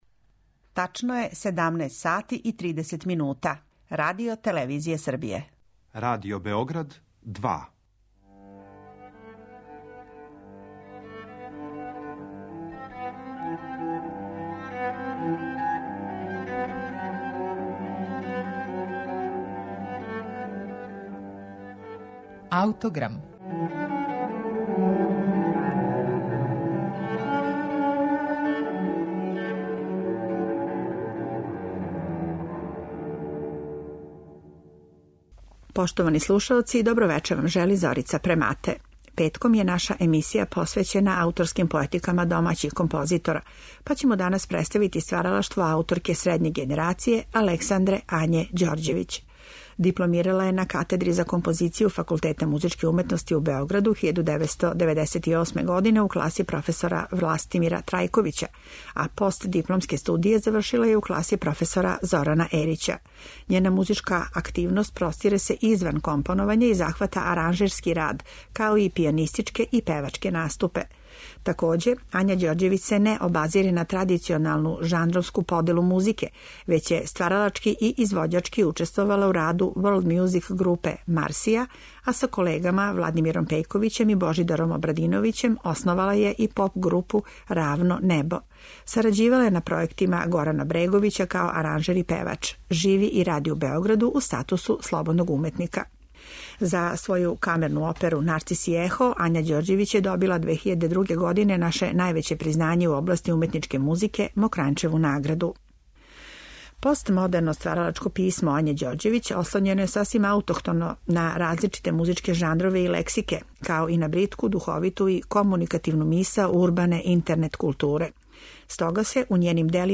кантату